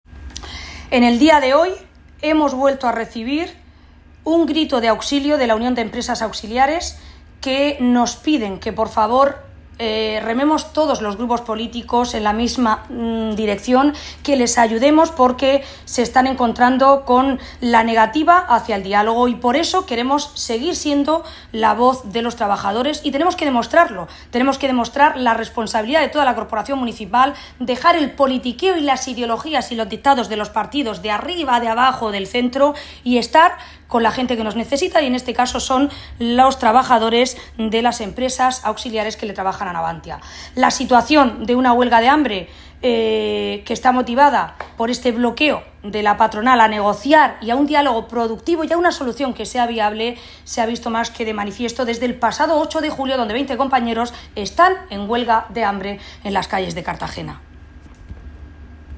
Audio: Declaraciones de Ana Bel�n Castej�n (1) (MP3 - 651,11 KB)